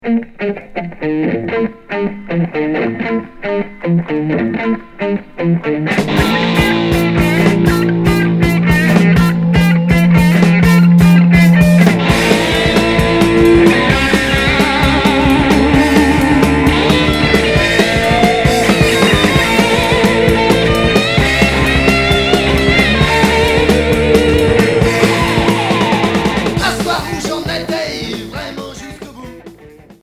Hard heavy